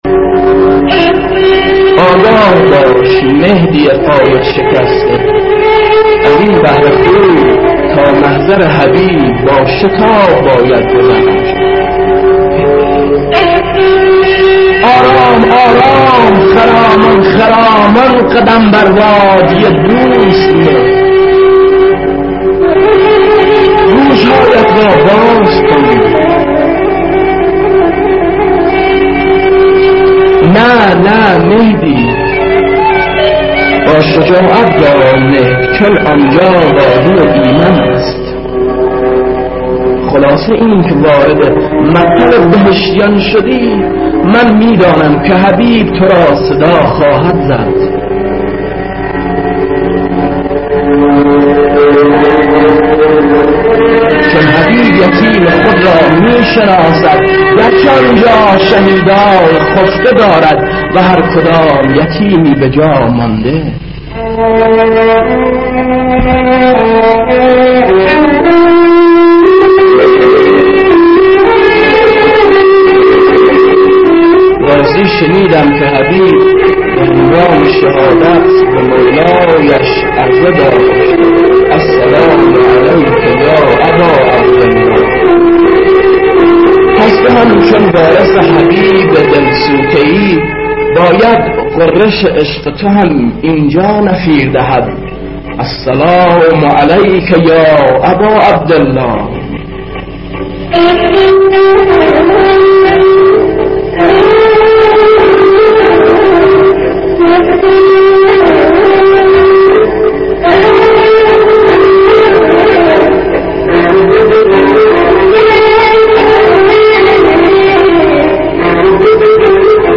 صوت/ دکلمه